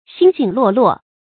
星星落落 注音： ㄒㄧㄥ ㄒㄧㄥ ㄌㄨㄛˋ ㄌㄨㄛˋ 讀音讀法： 意思解釋： 形容少而分散。